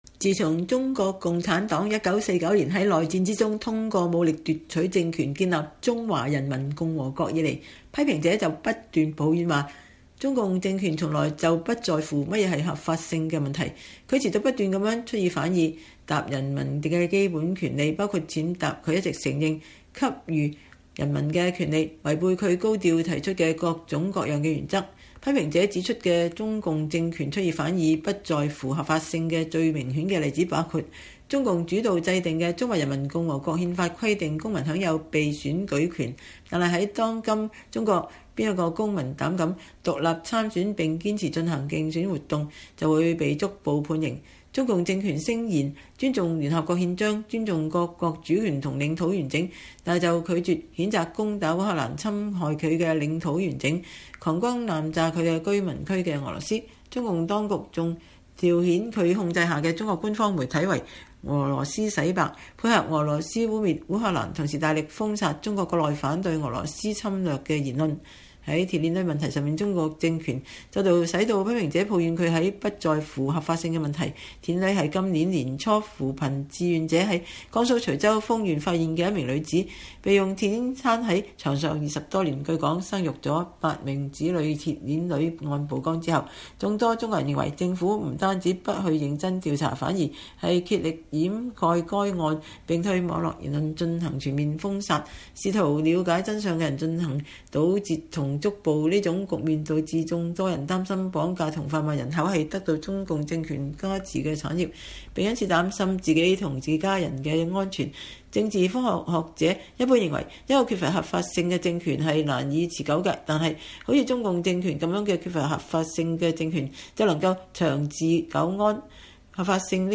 專訪黎安友（2）：談中國共產黨政權合法性問題